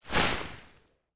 Free Frost Mage - SFX
forst_nova_short_05.wav